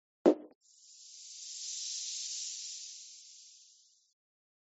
pop.mp3